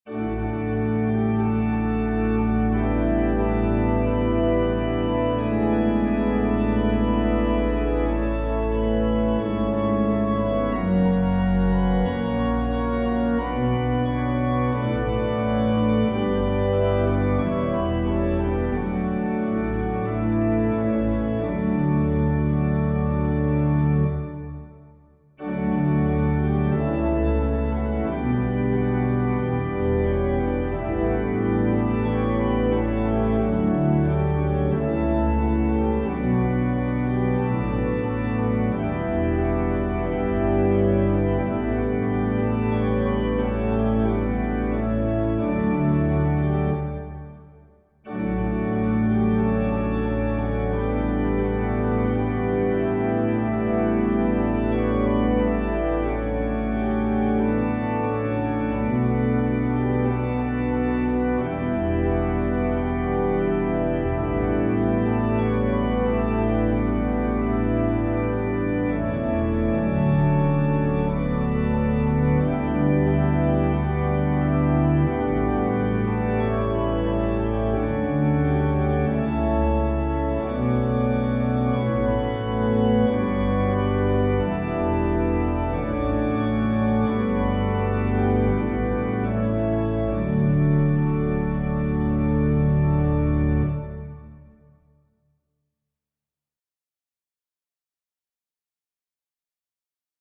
Organ Accompaniment for the hymn, Count Your Blessings, based off of the choral arrangement by Paul Busselberg.
This arrangement includes an introduction, a 2nd verse up to the chorus, and a full 3rd verse.
Voicing/Instrumentation: Organ/Organ Accompaniment We also have other 10 arrangements of " Count Your (Many) Blessings ".